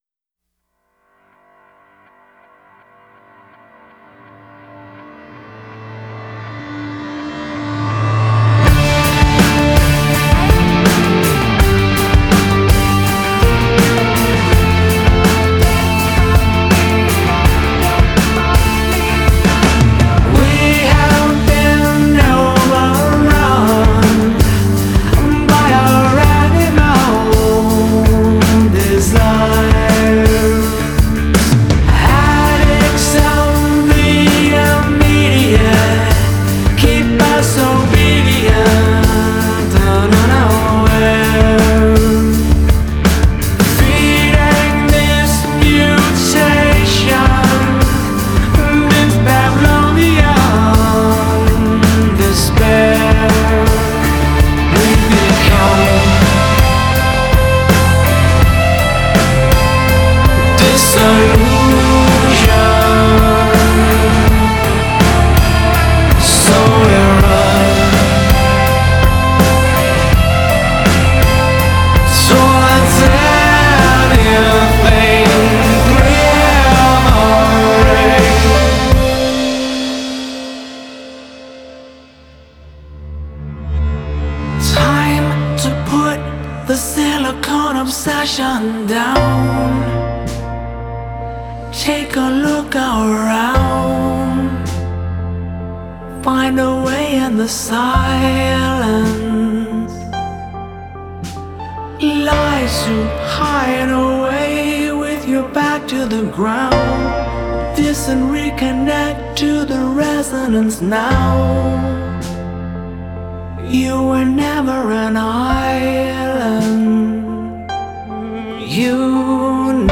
Rock Metal